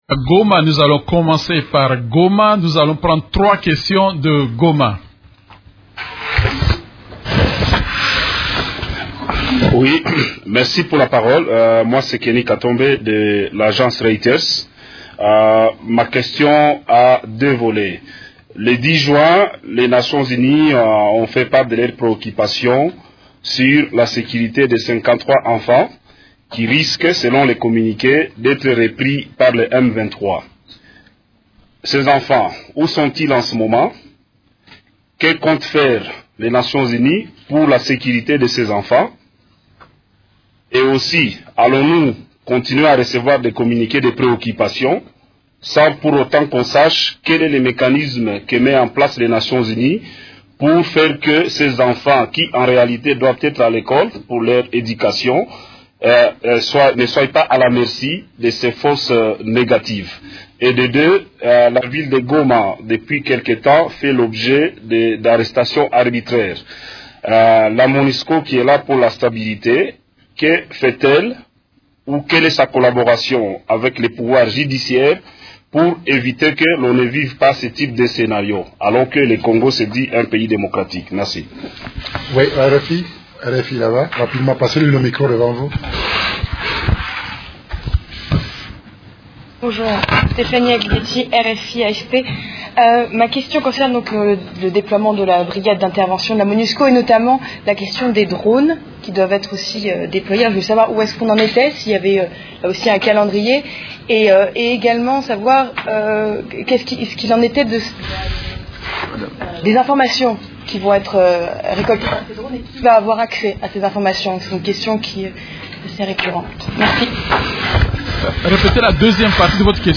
Conférence du 12 juin 2013
La conférence de presse hebdomadaire des Nations unies en RDC du mercredi 12 juin a porté sur les sujets suivants: